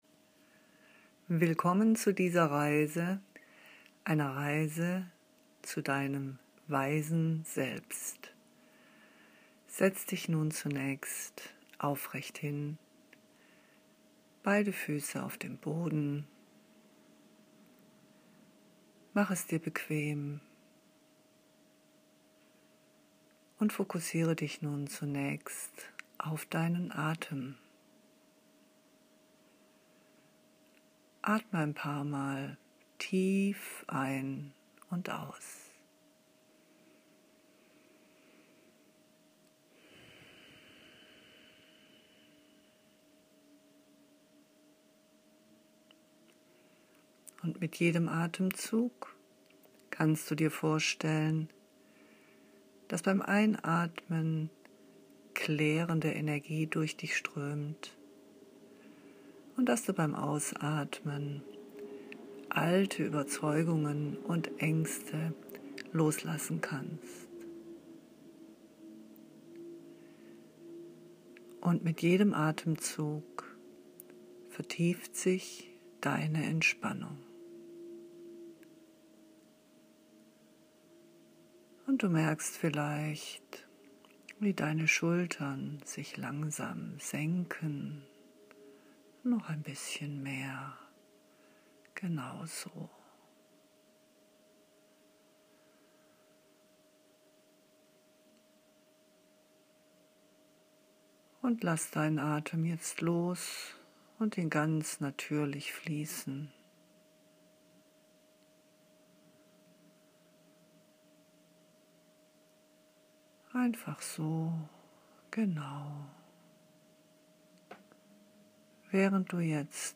Dafür habe ich heute eine kleine Meditation für dich aufgesprochen, eine Reise zu deinem weisen Selbst.
Schalte dein Telefon aus, mach das Fenster zu und lausche einfach der Stimme, die dich führt …